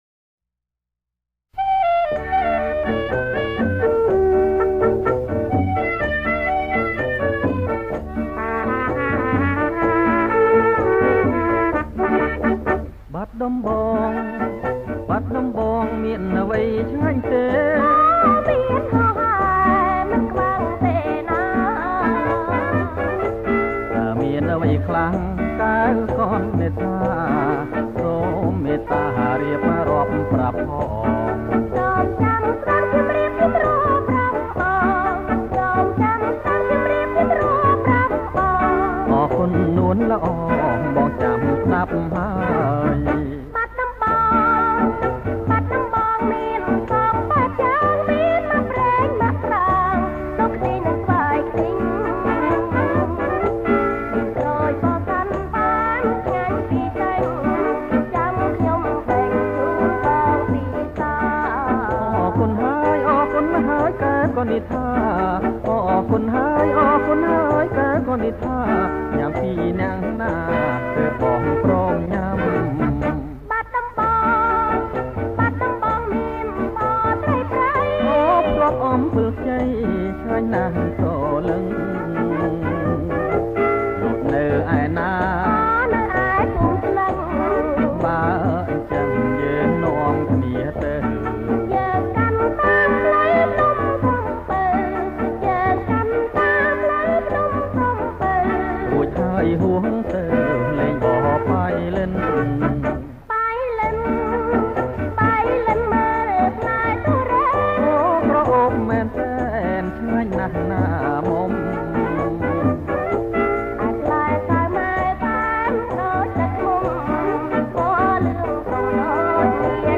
• ប្រគំជាចង្វាក់ រាំវង់